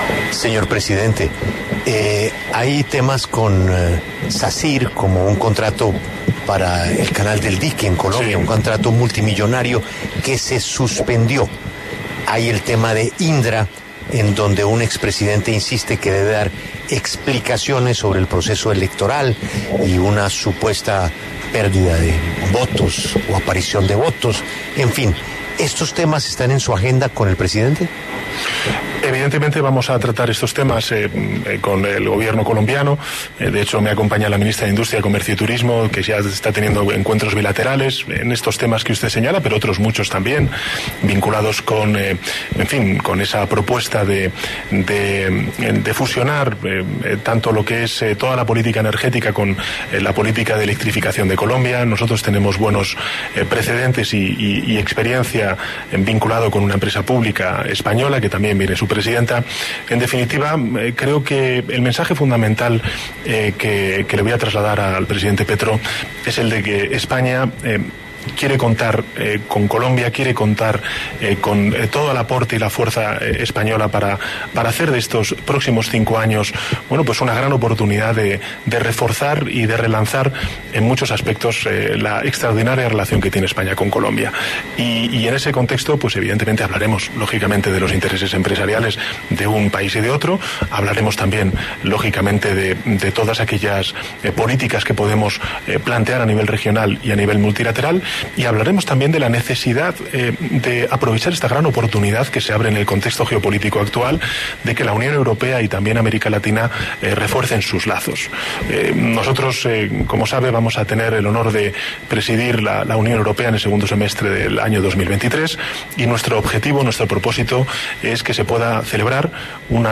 Pedro Sánchez, el presidente del Gobierno español, conversó en exclusiva en La W sobre las relaciones que quiere mantener con Colombia en el marco de su visita a Bogotá.